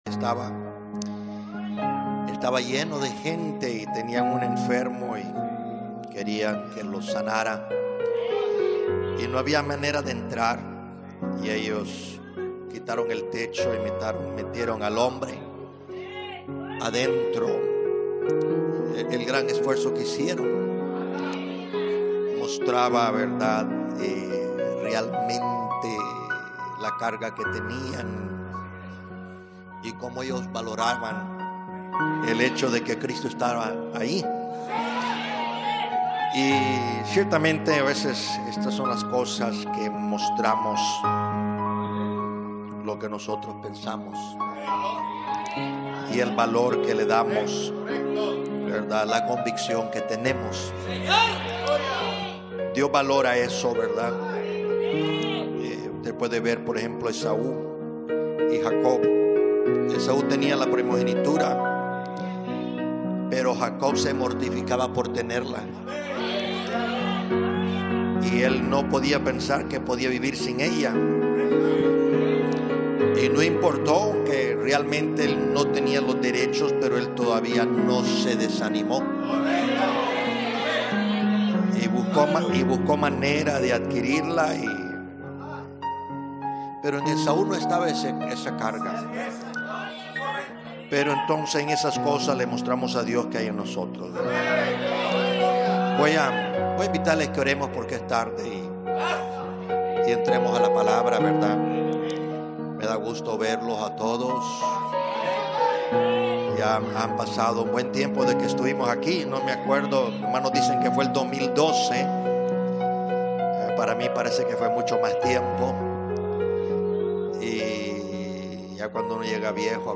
Estos mensajes fueron predicados en distintas Iglesias en San Miguel, El Salvador.